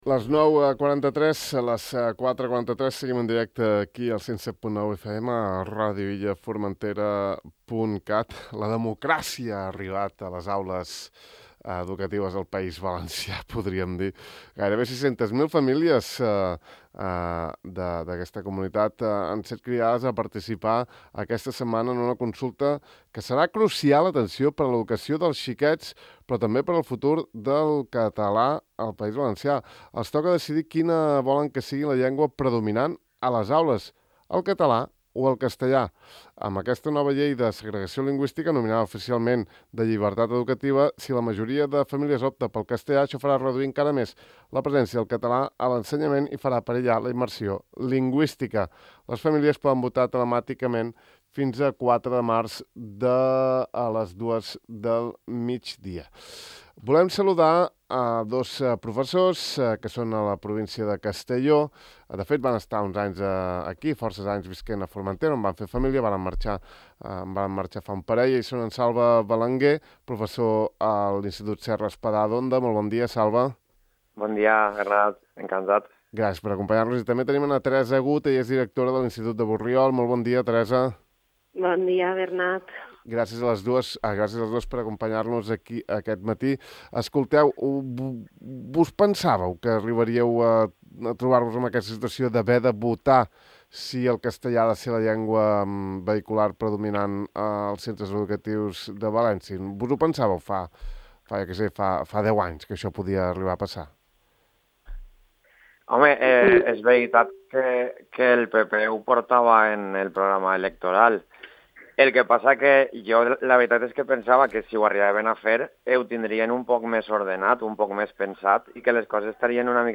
En parlam amb dos docents que actualment exerceixen a Castelló i que fa pocs anys van ensenyar a Formentera.